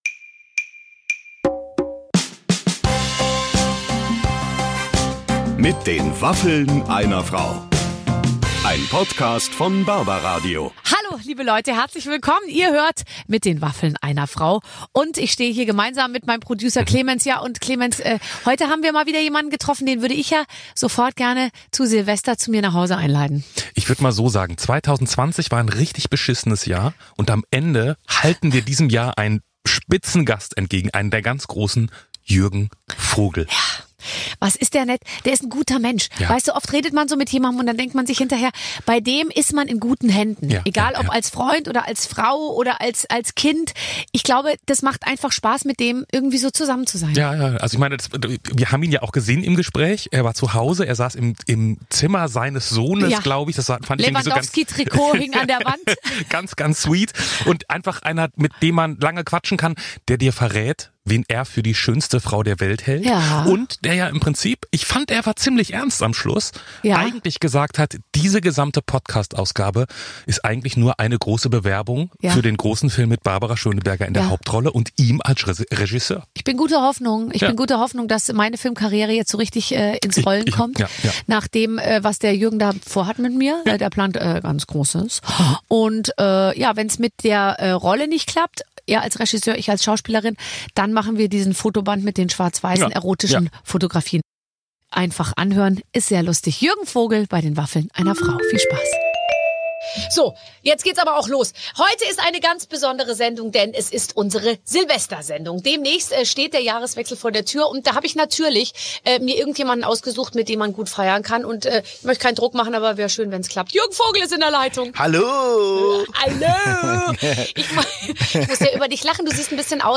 Jürgen Vogel zu Gast im Silvester-Podcast bei Barbara Schöneberger! Barbara Schöneberger konnte Jürgen Vogel entlocken, wer für ihn die schönste Frau der Welt ist.